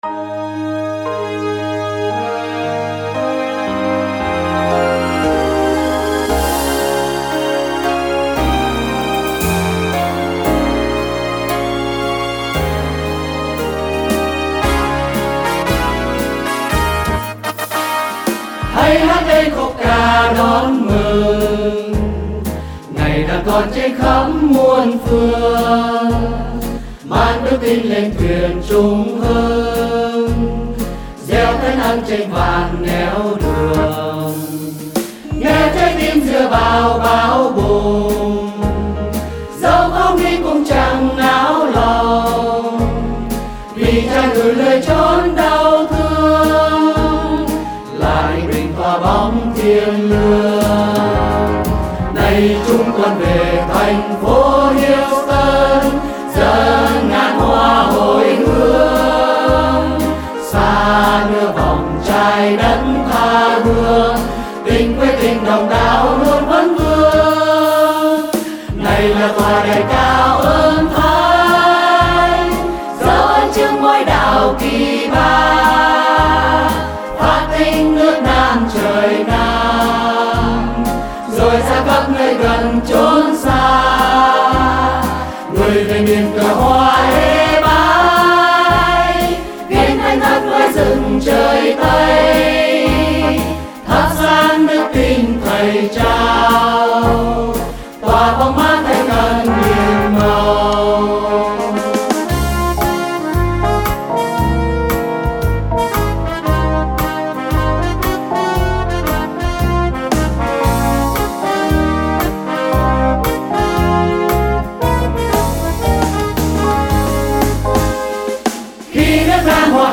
Thể Loại Đạo Ca
Tốp (G#)